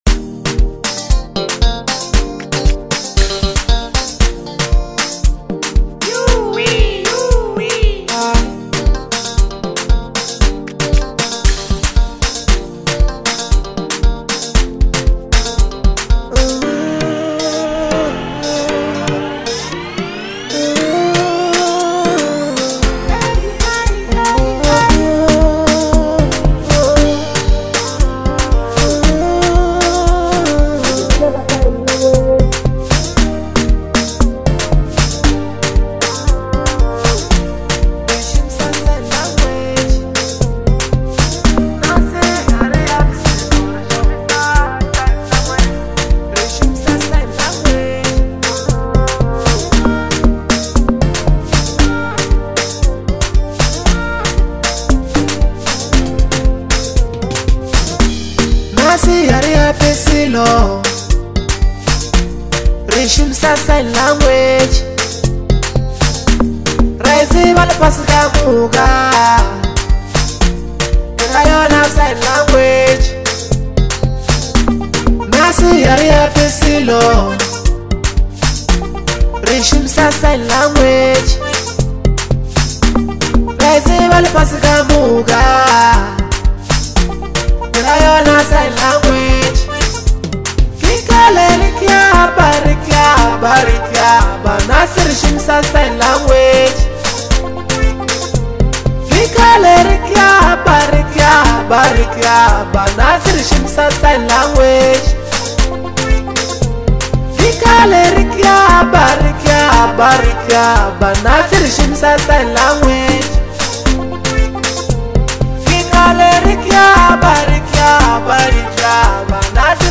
Bolo house